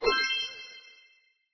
snd_exit_noise.ogg